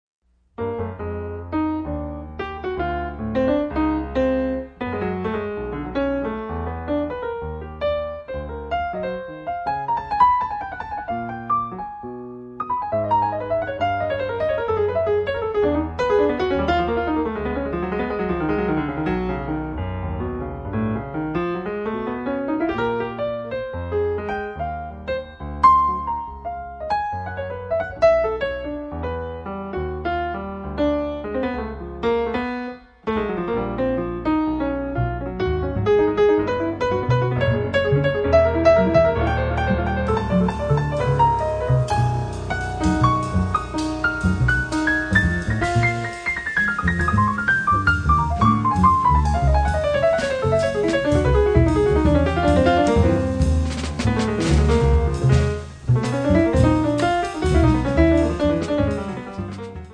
pianoforte
basso
batteria
un solo tribale